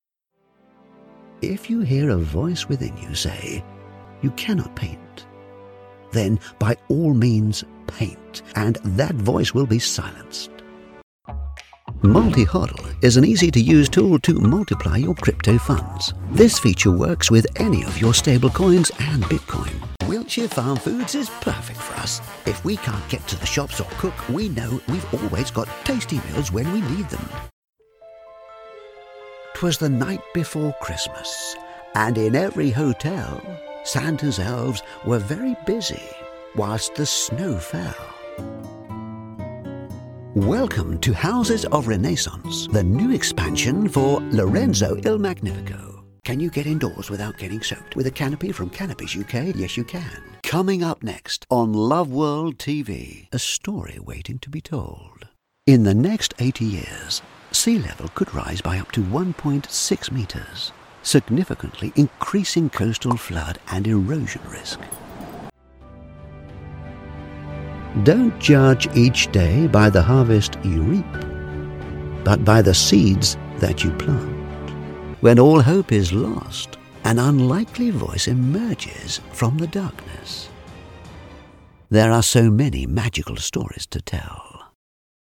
Male
English (British)
Adult (30-50), Older Sound (50+)
A full-time British voice over artist, with over 18 years experience.
Main Demo
Voiceover Showreel